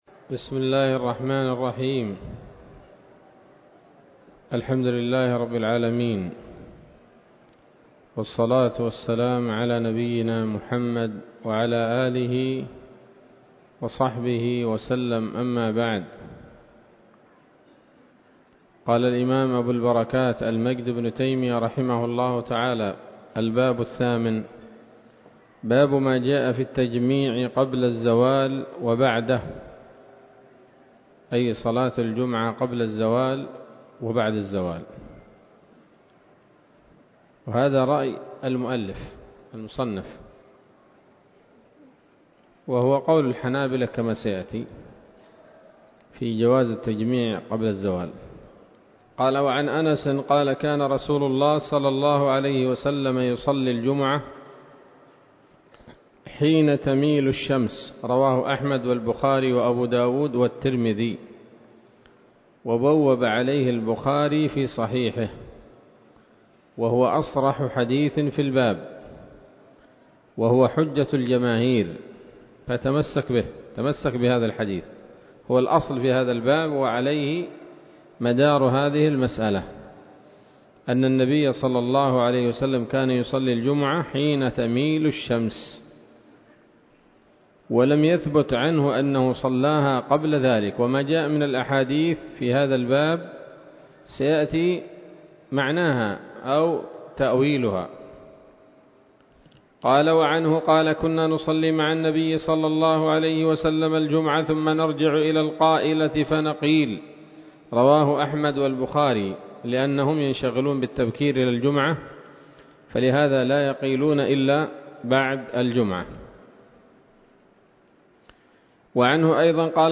الدرس الثالث والعشرون من ‌‌‌‌أَبْوَاب الجمعة من نيل الأوطار